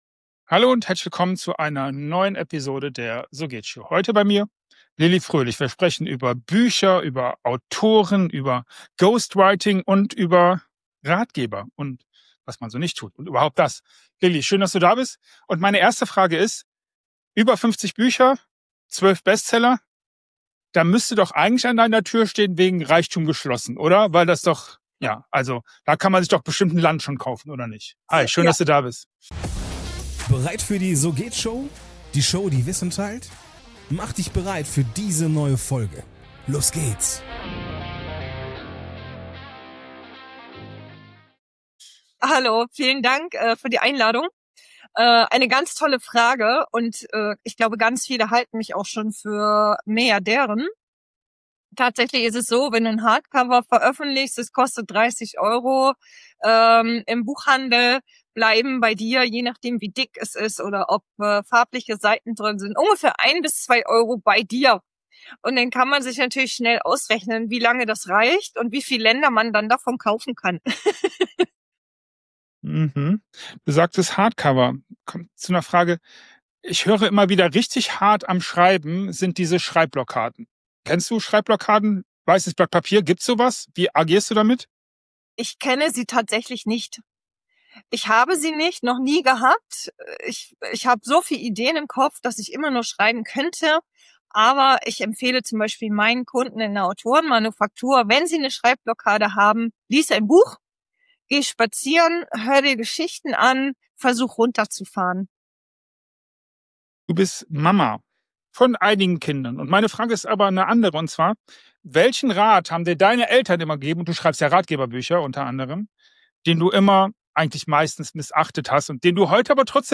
Die „So-Geht-Show“ verfolgt eine andere Idee: 30 Minuten ungefilterte Antworten und viele viele Fragen.
Keine Nachbearbeitung, keine zweiten Versuche – was gesagt wird, bleibt.